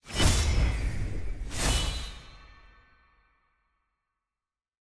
sfx_ui_combat_victory.ogg